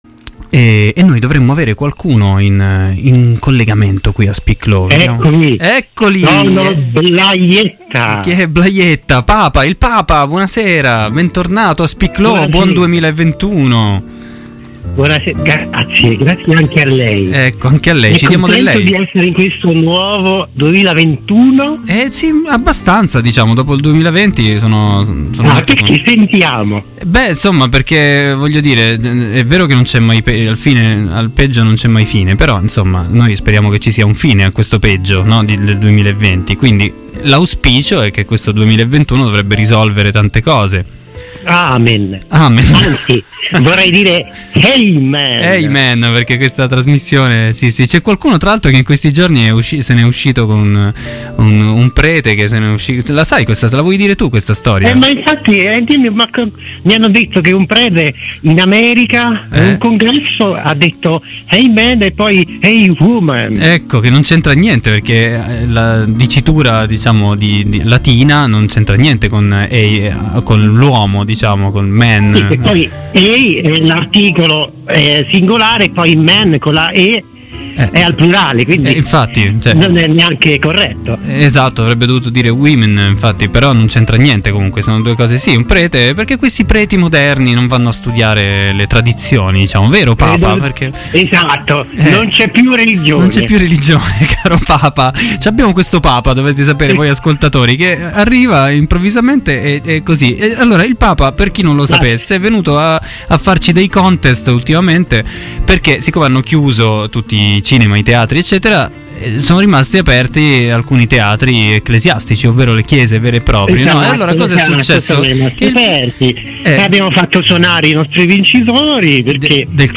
Sua Santità, il III Papa, irrompe negli studi di Radio Città Aperta, con l'intervento a sorpresa nientepocodimeno che di Maleficent!